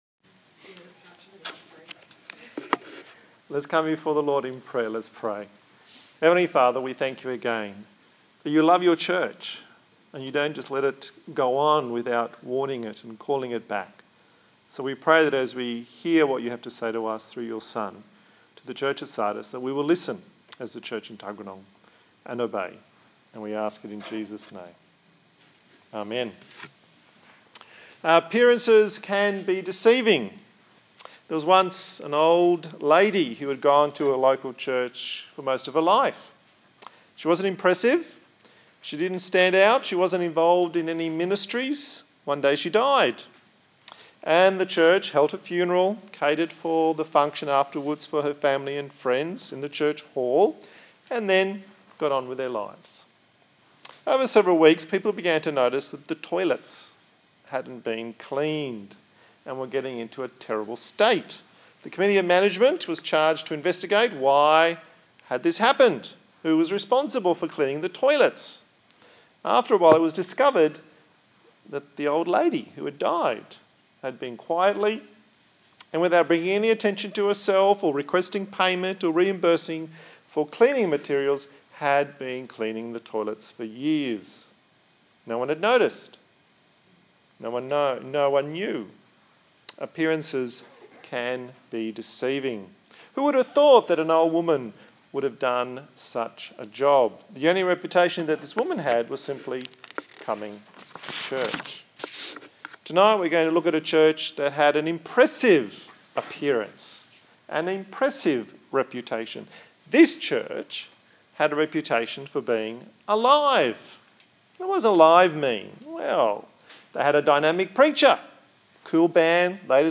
A sermon in the series on the book of Revelation
Service Type: TPC@5